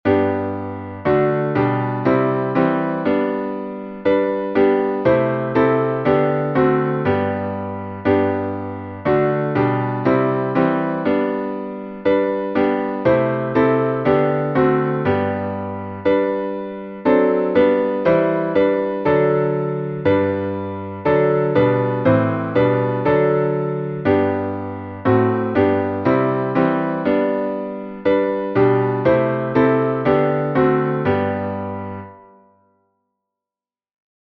Downloads Áudio Áudio cantado (MP3) Áudio instrumental (MP3) Áudio intrumental (MIDI) Partitura Partitura 4 vozes (PDF) Cifra Cifra (PDF) Cifra editável (Chord Pro) Mais opções Página de downloads
salmo_125B_instrumental.mp3